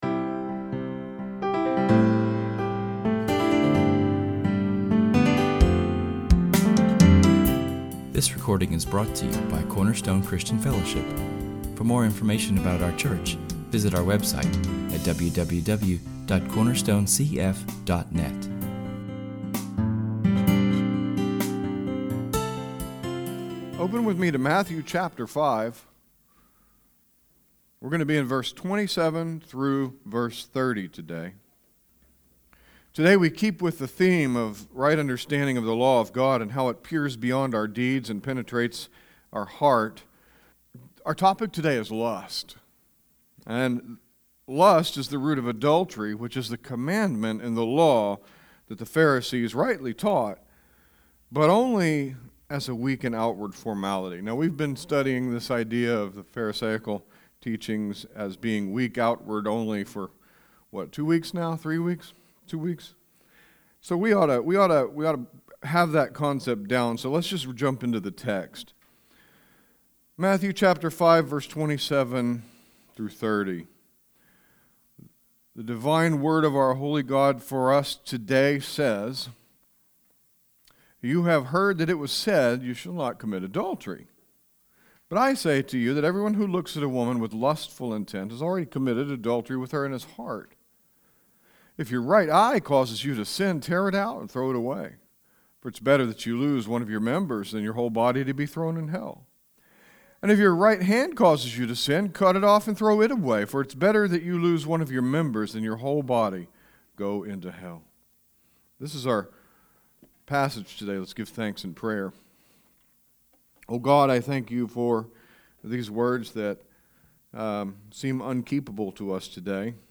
We will split our message from [esvignore]Matthew 5:27-30[/esvignore] into 2 sermons, this one entitled Sexual Sin – Part 1 and a second sermon to address some strategies for defeating lust in part 2.